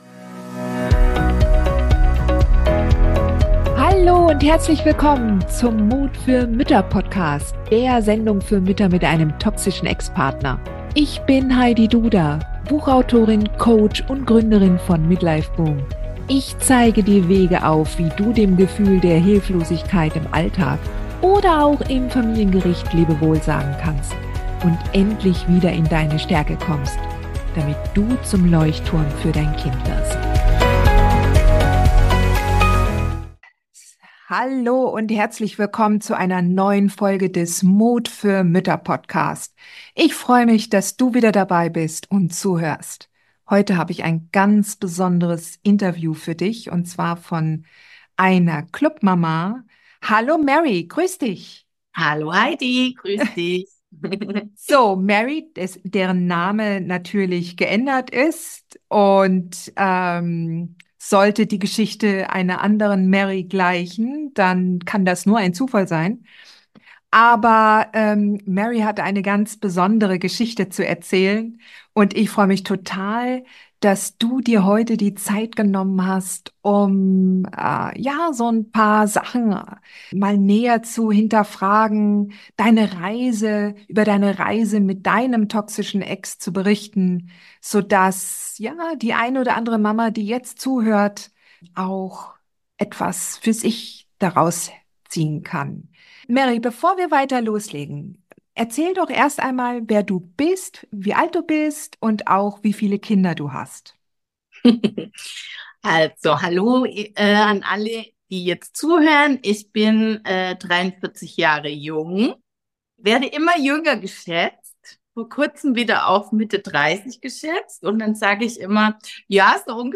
Ein Mutmach-Interview für alleinerziehende Mütter